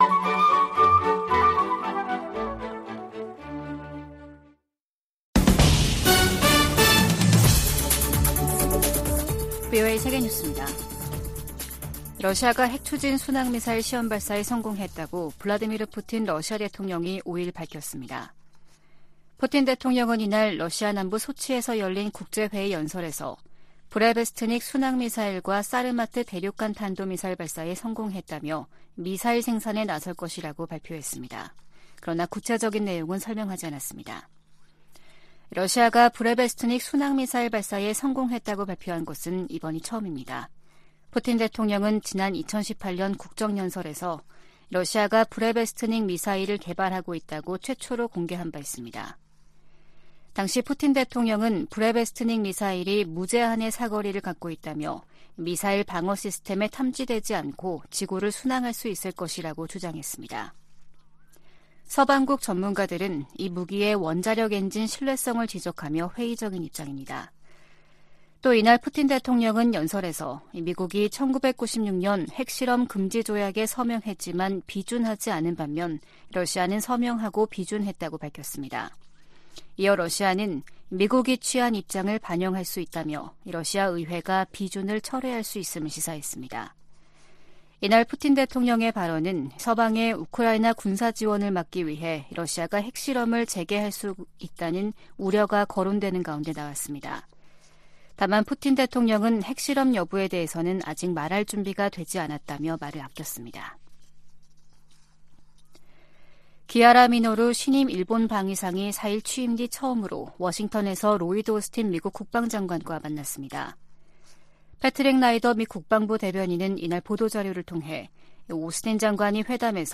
VOA 한국어 아침 뉴스 프로그램 '워싱턴 뉴스 광장' 2023년 10월 6일 방송입니다. 로이드 오스틴 미 국방장관과 기하라 미노루 일본 방위상이 북한의 도발과 중국의 강압, 러시아의 전쟁을 미-일 공통 도전으로 규정했습니다. 미국 국무부가 제재 대상 북한 유조선이 중국 영해에 출몰하는 데 대해, 사실이라면 우려한다는 입장을 밝혔습니다. 한국 헌법재판소가 대북전단금지법에 위헌 결정을 내리면서 민간단체들이 살포 재개 움직임을 보이고 있습니다.